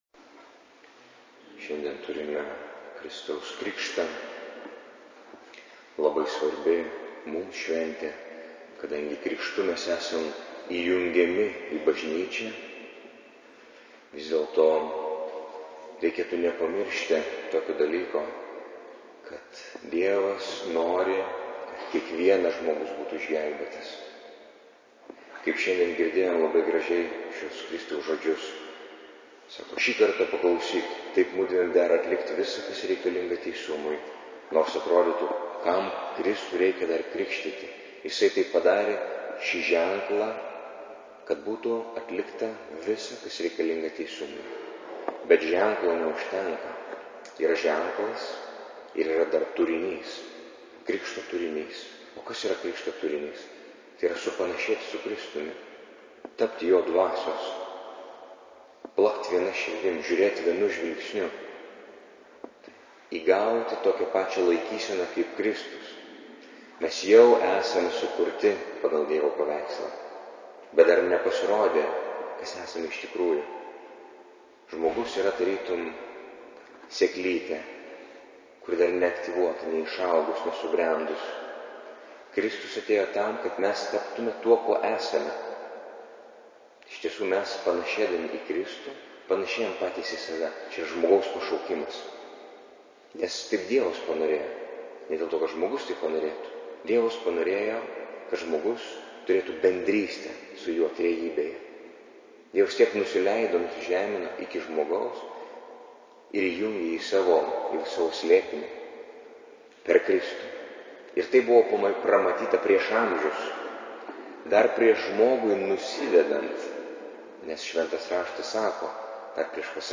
Audio pamokslas Nr1: